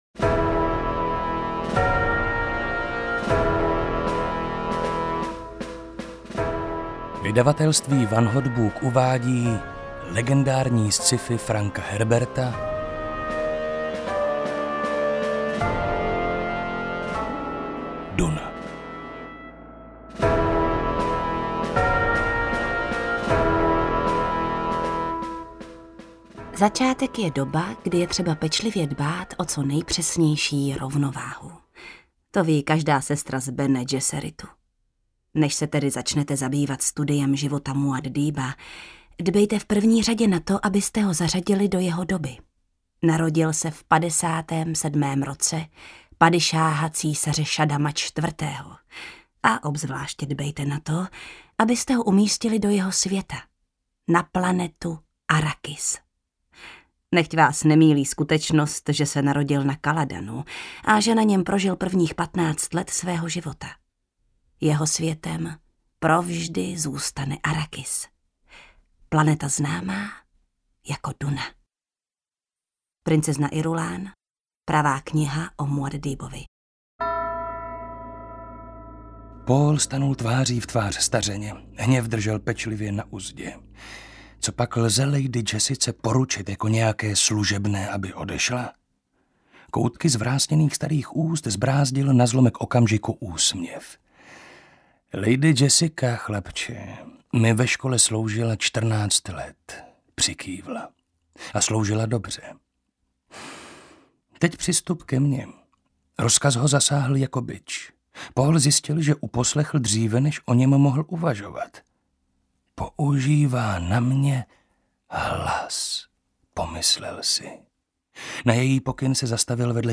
DUNA audiokniha
Ukázka z knihy
Vyprávění spletitého příběhu se ujal Marek Holý, jehož na počátku každé kapitoly doprovází Jana Stryková, která přednáší citace z pamětí princezny Irulán.„Naprostý unikát sci-fi literatury… nenapadá mě jiné dílo, s nímž by byla Duna srovnatelná, snad kromě Pána prstenů.“‒ Arthur C. Clarke„Silné, přesvědčivé, geniální dílo.“‒ Robert A. Heinlein „Jeden z milníků moderní science fiction.“‒ Chicago Tribune „Dokonalý a propracovaný portrét mimozemské společnosti, jaký dosud nedokázal nastínit žádný ze žánrových autorů…
• InterpretJana Stryková, Marek Holý